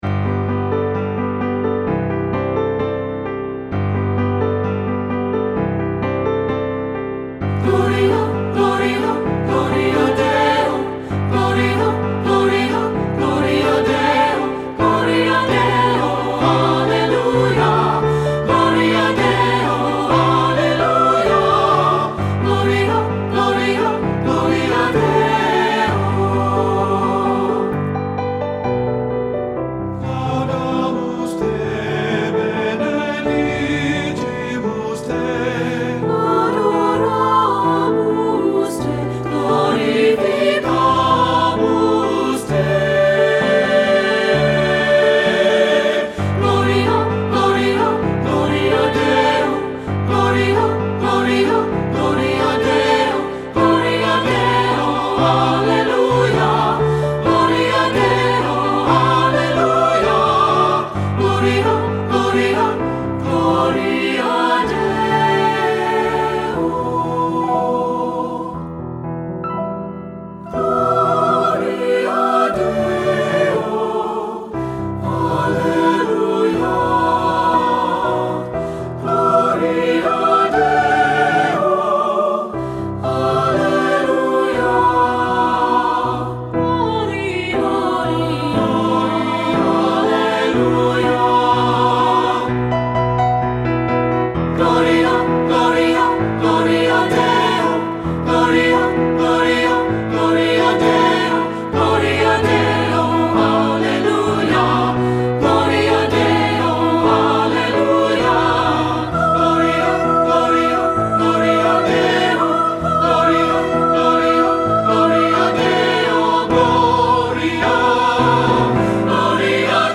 Three-Part Mixed Voices with Piano
• Piano
Studio Recording
Pulsating rhythmic and contrasting majestic phrases
Ensemble: Three-part Mixed Chorus
Accompanied: Accompanied Chorus